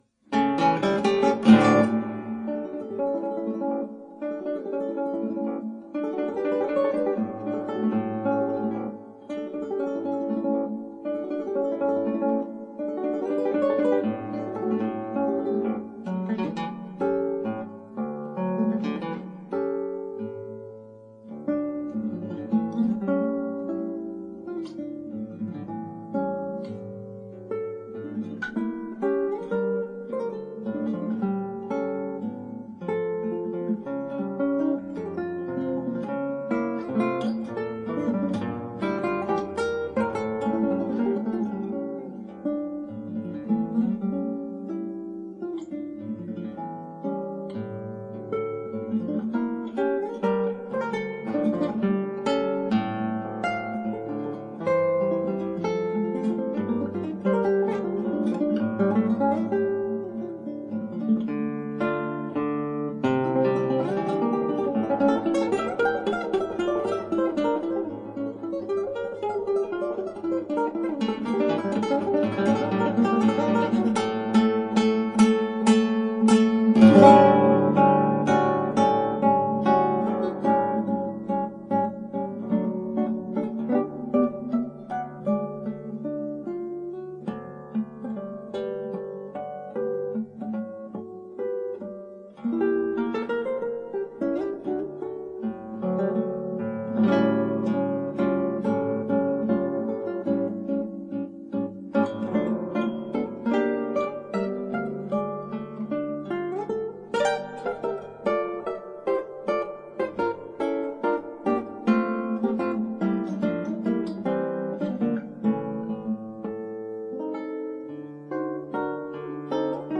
クラシックギター　ストリーミング　コンサート
しかし、彼のピアノ曲は「も～スペイン」というかんじで、よくギターに
へっぽこギター